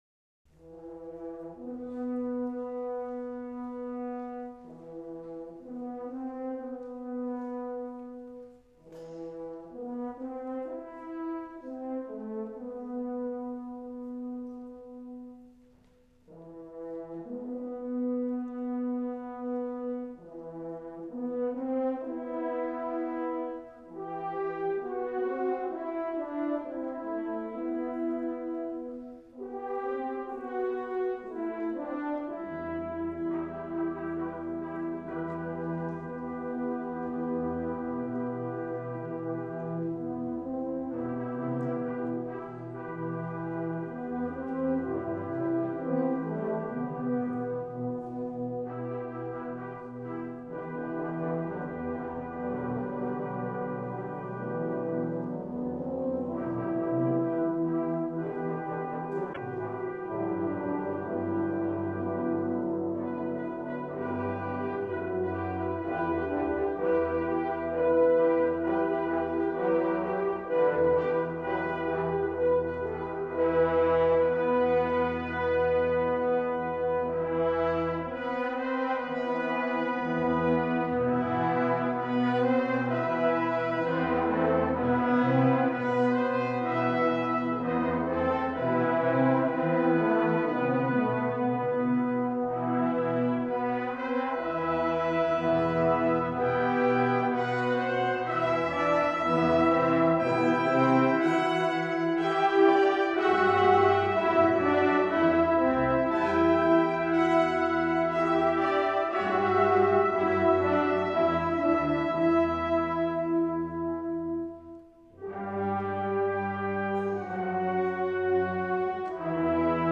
Fanfare for the Unheralded (MP3) Live performance by DC's Different Drummers (November 2002)
fanfare.mp3